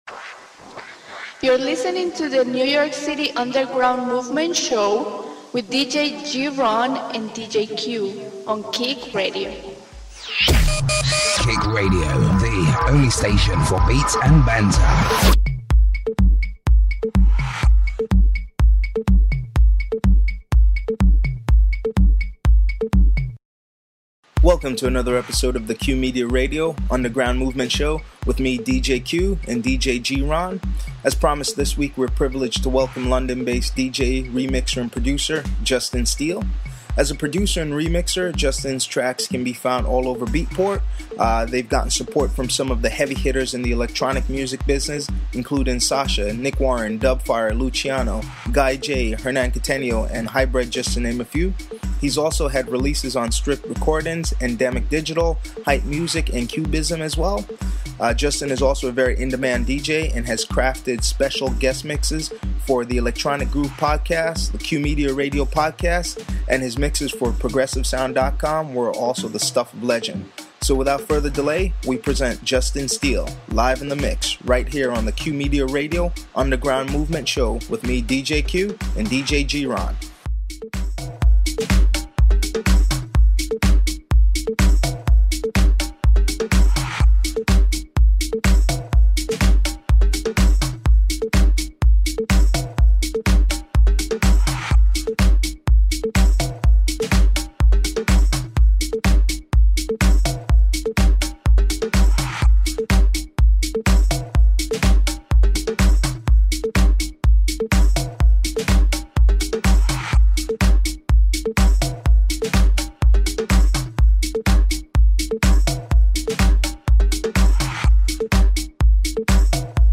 special guest mix
deep, laid back grooves and summer funk!